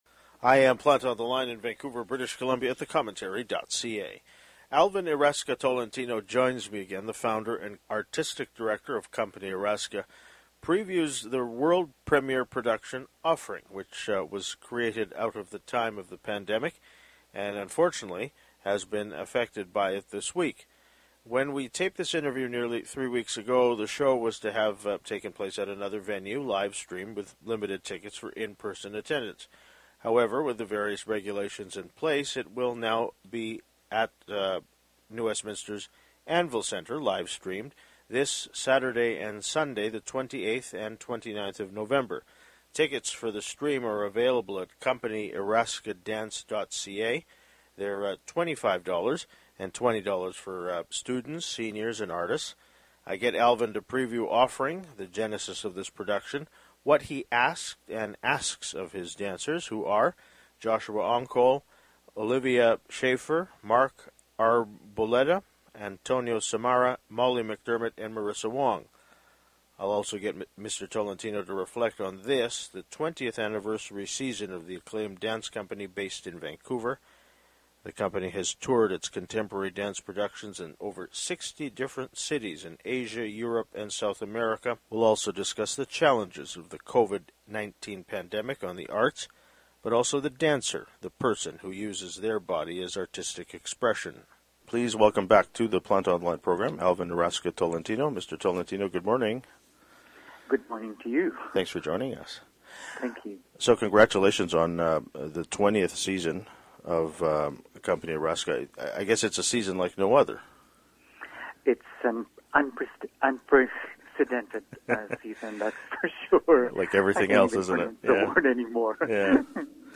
Text of introduction
in Vancouver, British Columbia